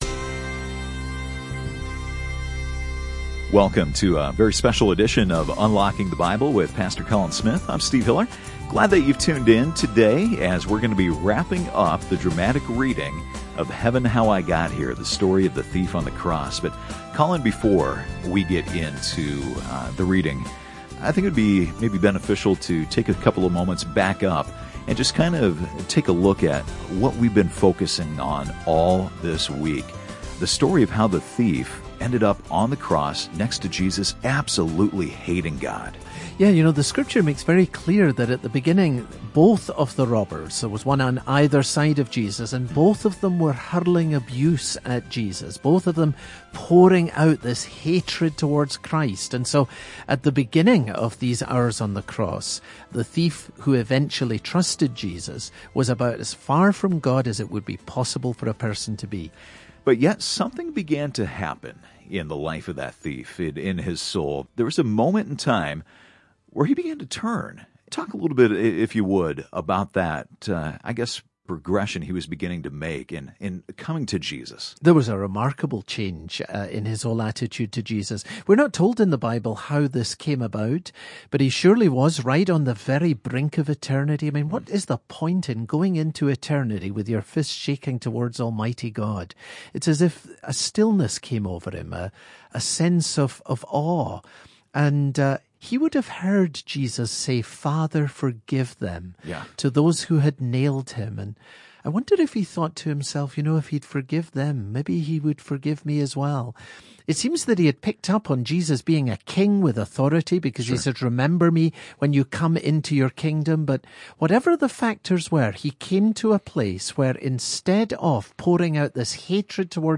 That’s what happened to the thief on the cross, who died a few feet from Jesus. Heaven, How I Got Here is his story, told in his own words, as he looks back from Heaven on the day that changed his eternity, and the faith that can change yours. This radio broadcast features narration by actor Stephen Baldwin.